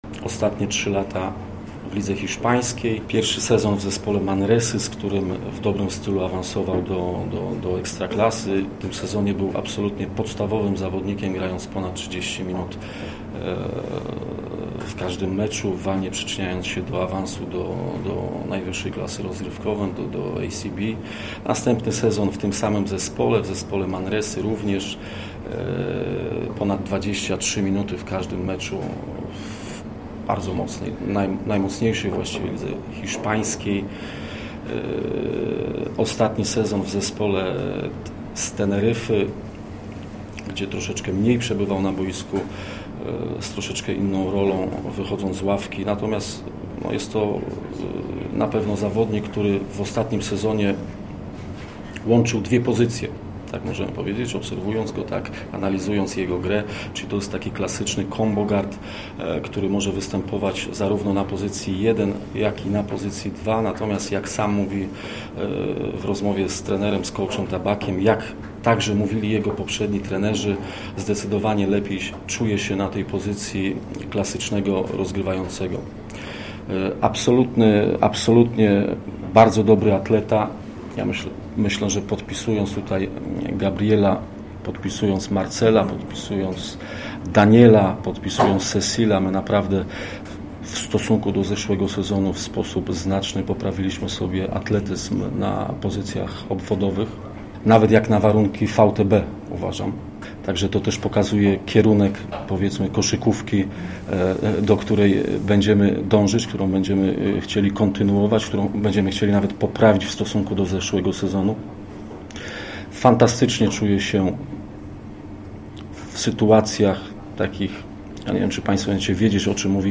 Sztab trenerski na konferencji prasowej, na której mówiono o Duńczyku